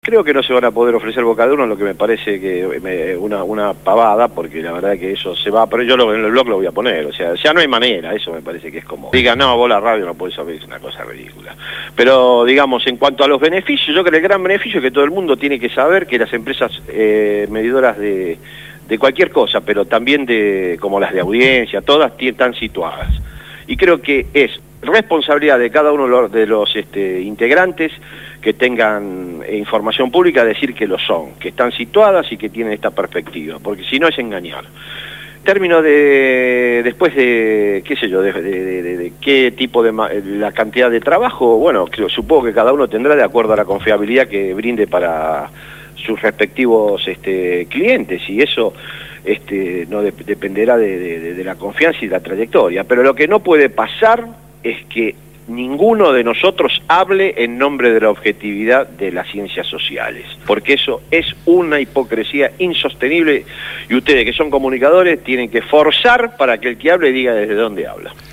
Verborrágico, locuaz.